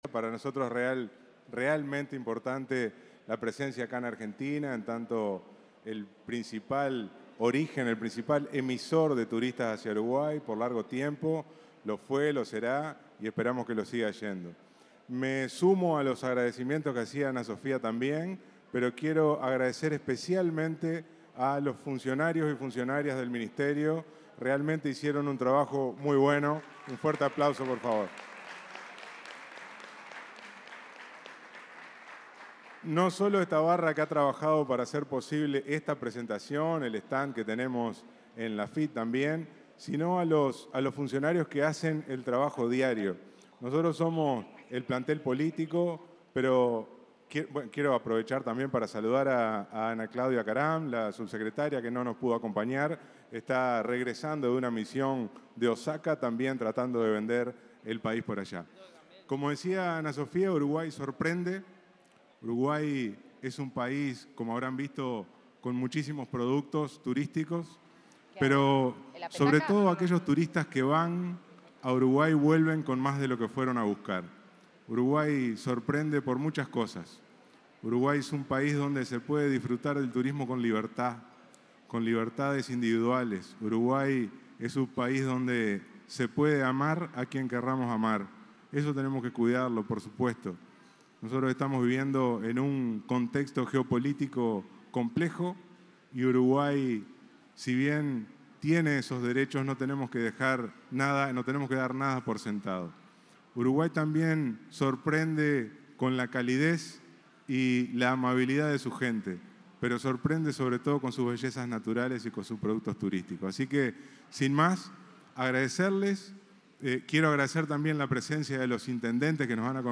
Declaraciones del ministro de Turismo, Pablo Menoni